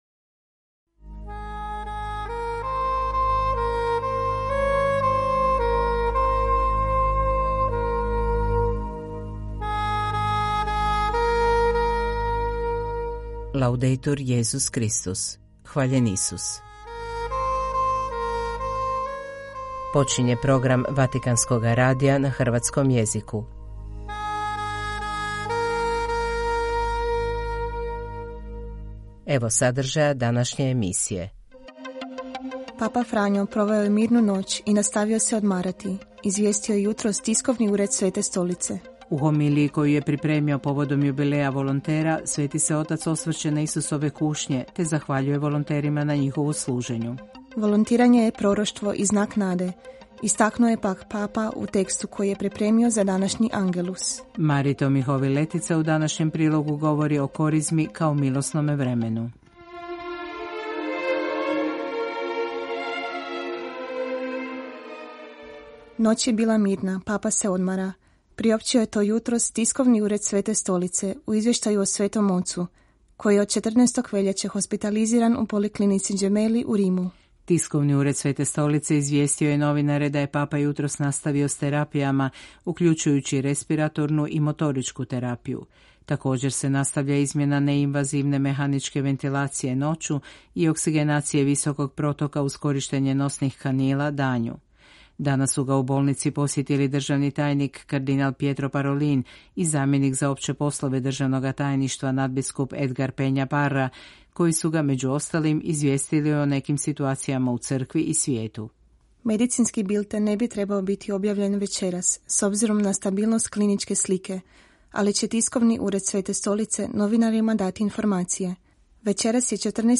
Vatican News - Hrvatski Program 214 subscribers updated 24h ago 購読 購読済 再生 再生中 シェア すべての項目を再生済み／未再生としてマークする シリーズのホーム • Feed Manage series 176612 コンテンツは Radio Vaticana - Vatican News によって提供されます。